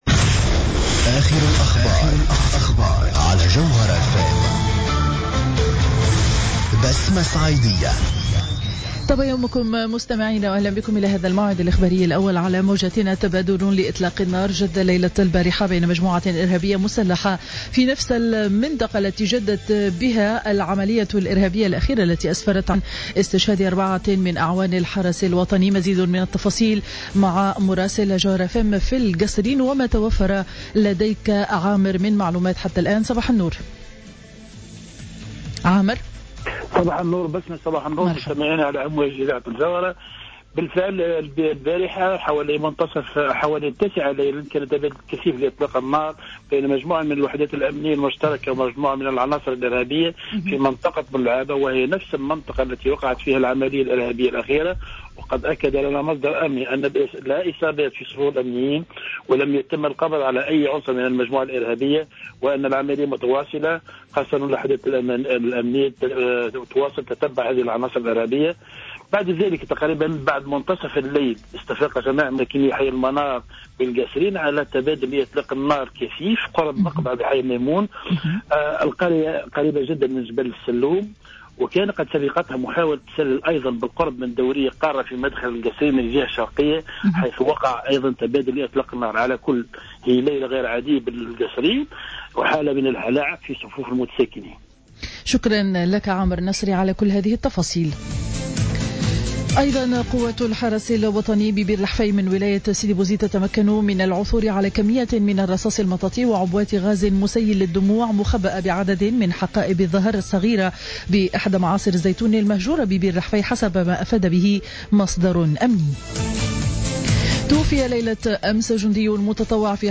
نشرة أخبار السابعة صباحا ليوم الاثنين 09 مارس 2015